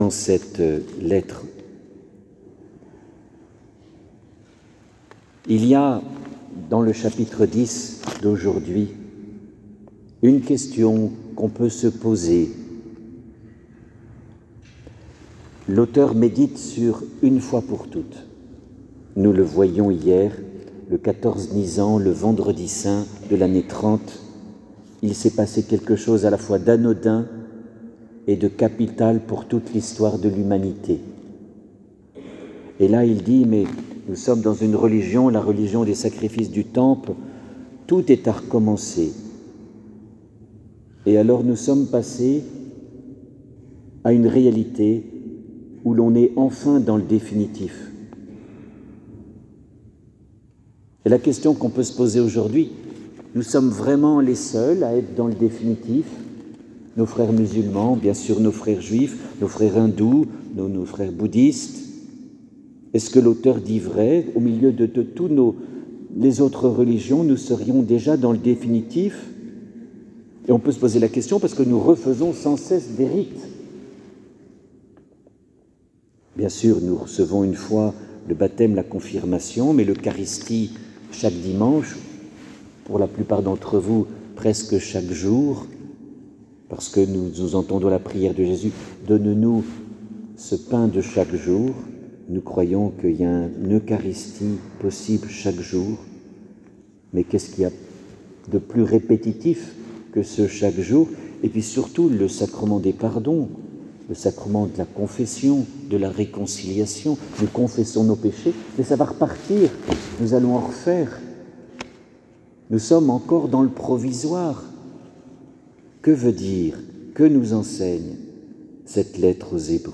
les homélies « Préparons notre Résurrection » – Eglise Saint Ignace